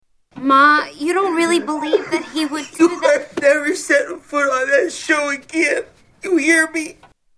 Tags: Movie Musical Hairspray Hairspray movie clips John Travolta